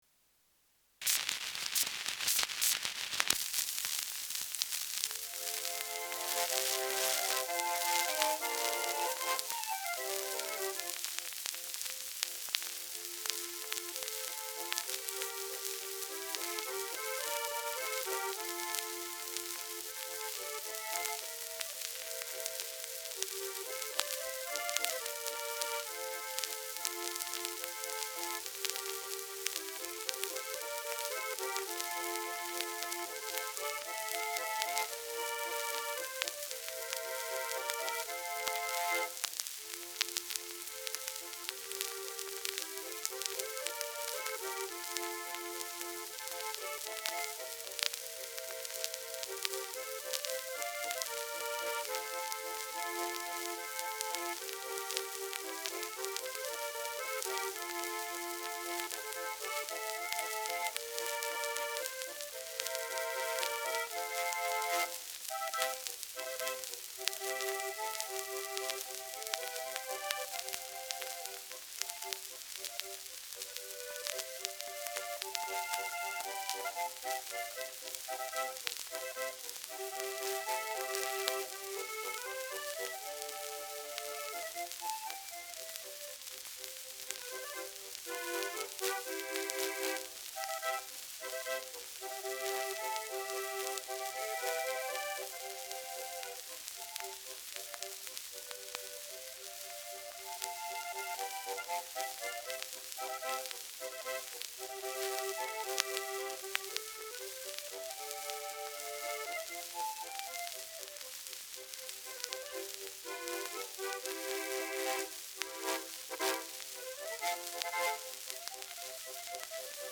Walzer
Schellackplatte
Handorgel-Solo
[Bern] (Aufnahmeort)